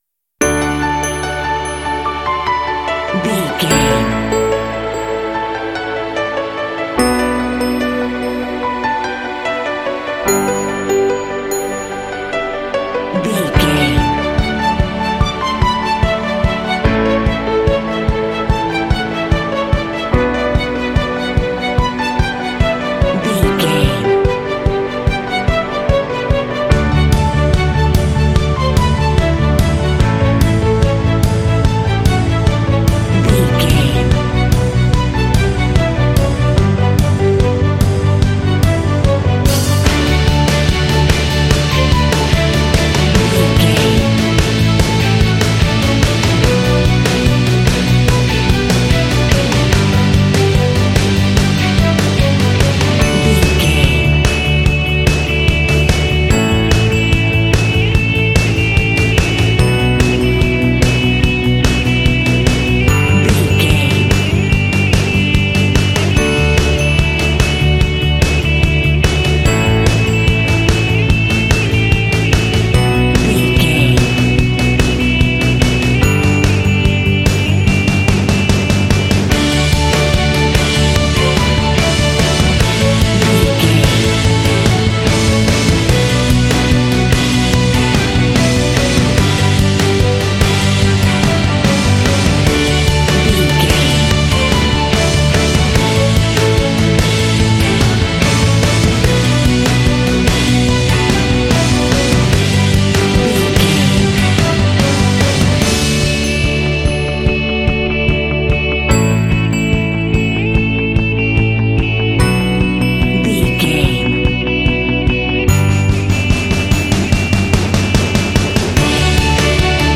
Uplifting
Ionian/Major
energetic
bouncy
cheerful/happy
strings
piano
drums
bass guitar
electric guitar
contemporary underscore
indie